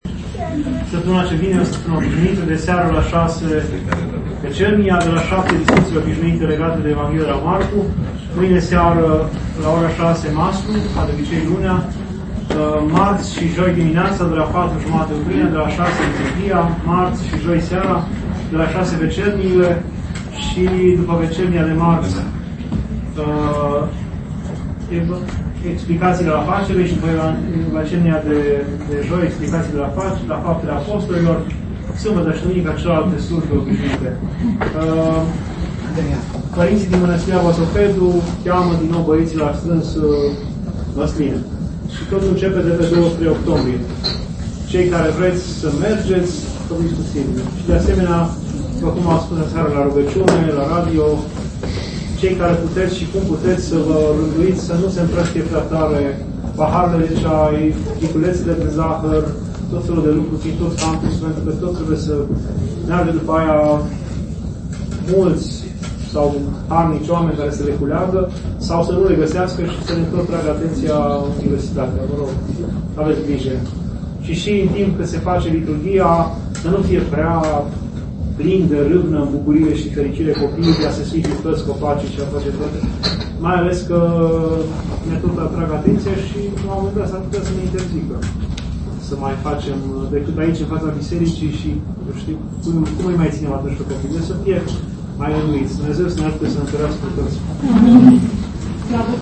Dumnezeiasca Liturghie